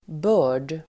Uttal: [bö:r_d]